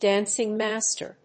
音節dáncing màster